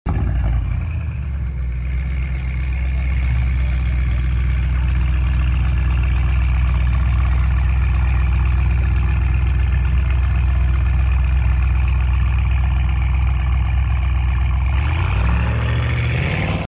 Absolute beast sounding RS6 (VOLUME sound effects free download